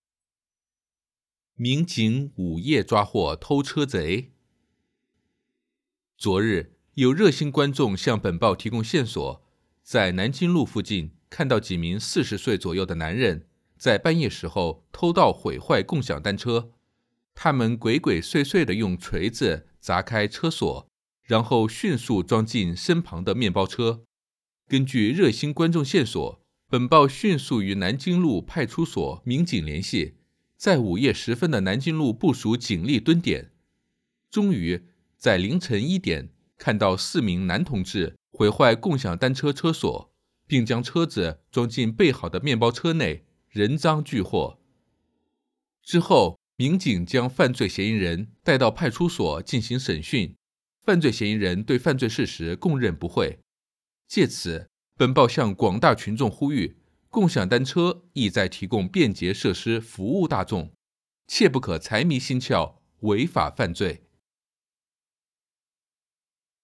Listen to the voice-over and answer the questions.